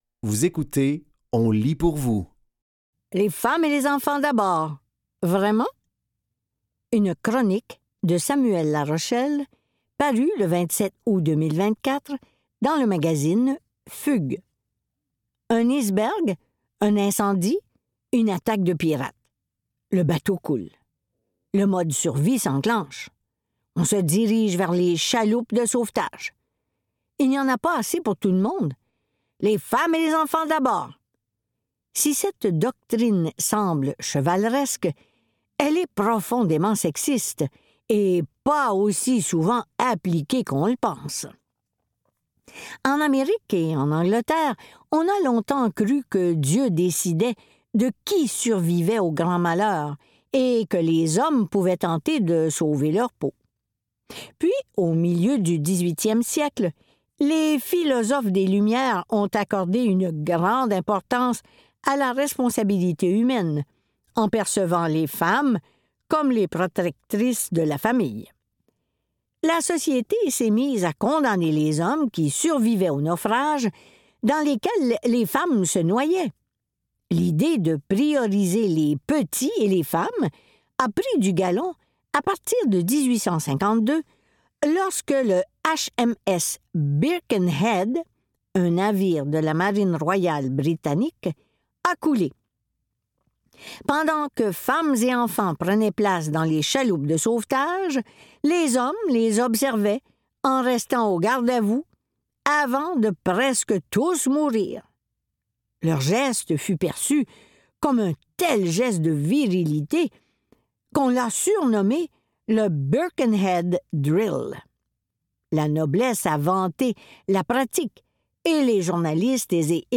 Dans cet épisode de On lit pour vous, nous vous offrons une sélection de textes tirés du média suivant : Fugues, Virage, ICI Nouvelle-Ecosse, et Le Devoir.